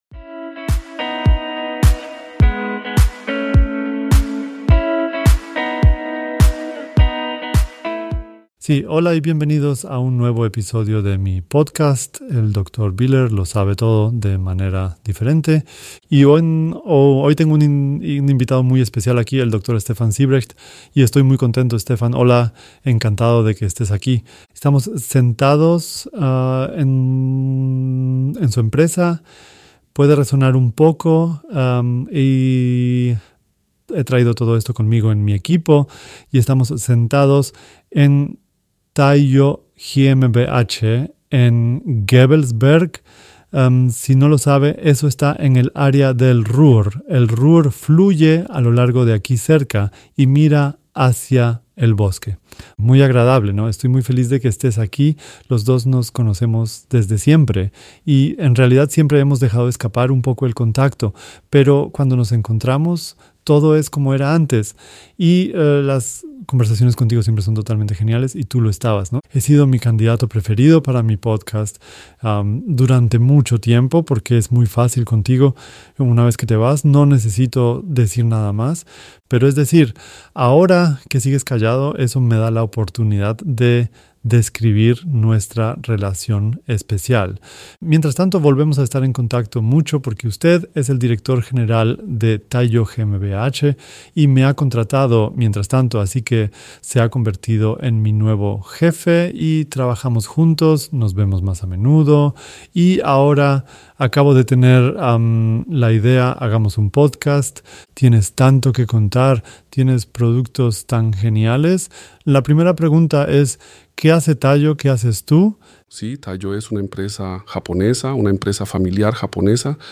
Esta es la traducción asistida por Ki del episodio 31